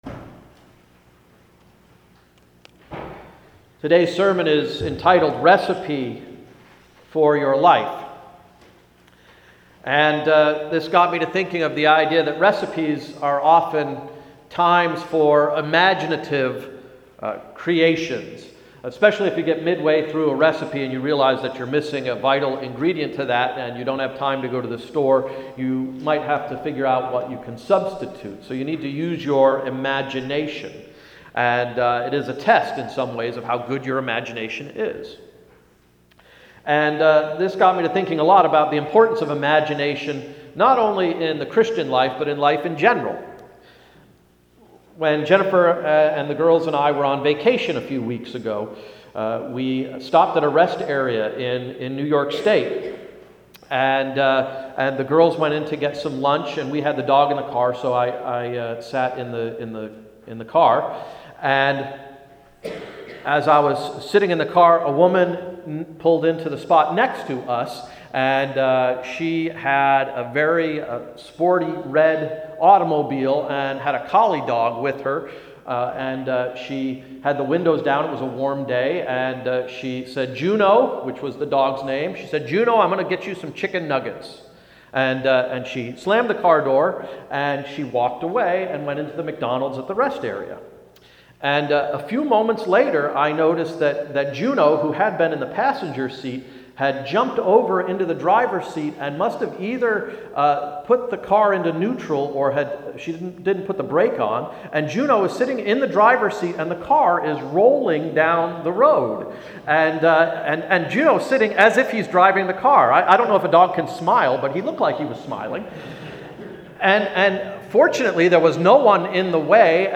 “Recipe for Your Life”: A sermon based on Exodus 16:2-15